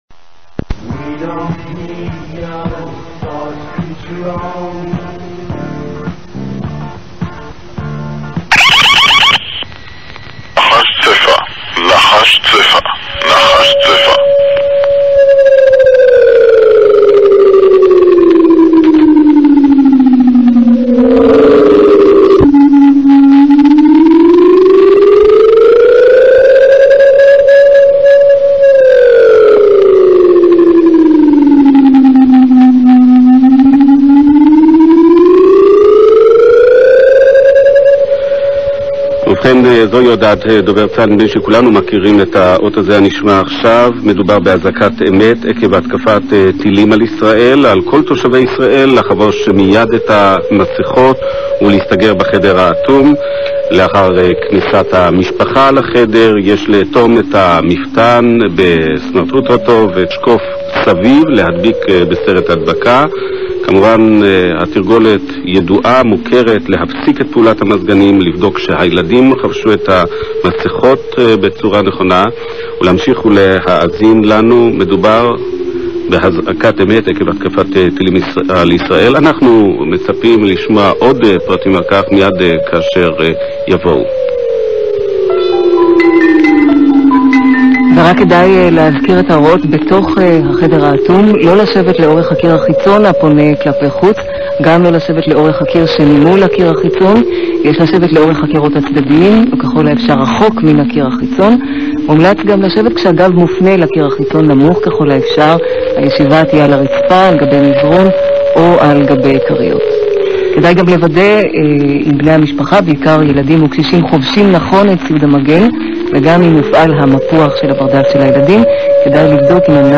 מלחמת המפרץ 1991, ''נחש צפע'', אזעקה פורצת ברדיו והוראות לכניסה לחדר האטום - Gulf War, Israeli Alarm